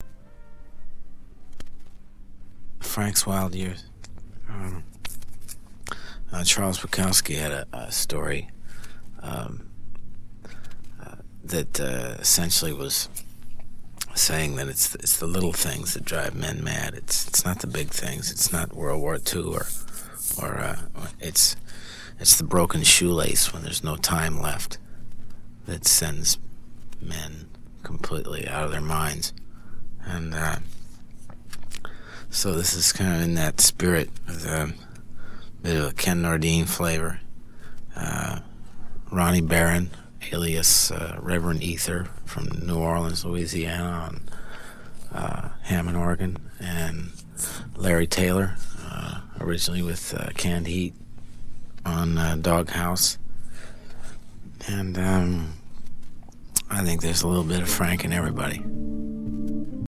Attachments Conversation-with-Tom-Waits_1983_Franks-Wild-Years_Bukowski_17.mp3 1.6 MB · Views: 178